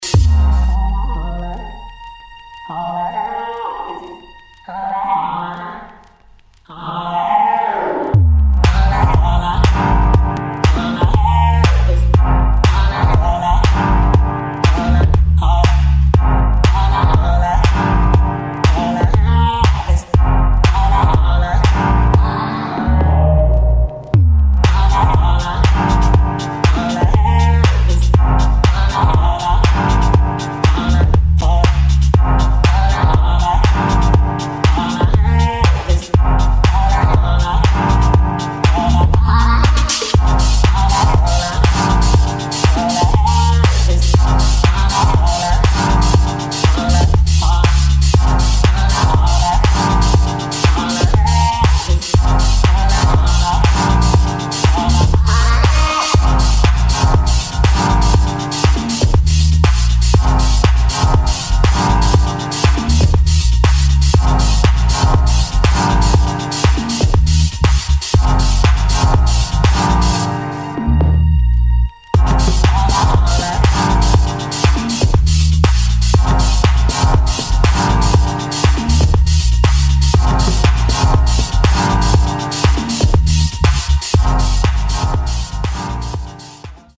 HOUSE | TECH HOUSE